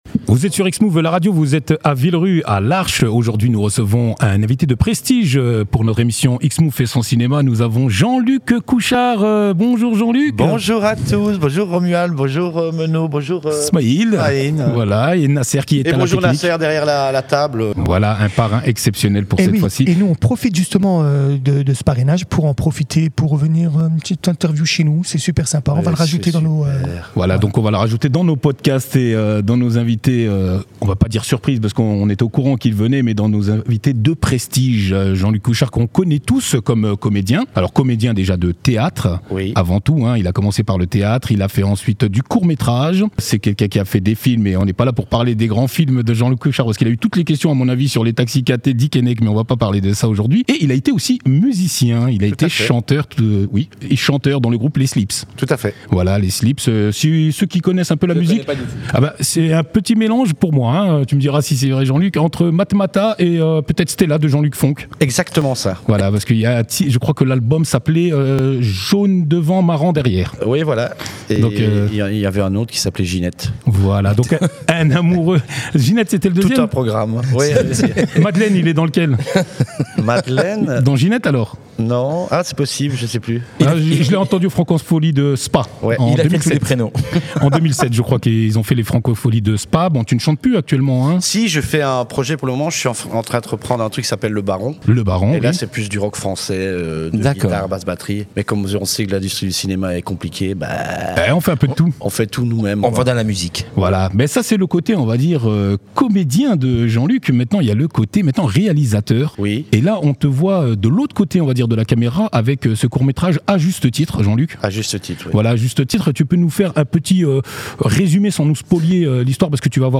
Boîte à images (Interviews 2025)
arch-x-move-la-radio-interview-jean-luc-couchard.mp3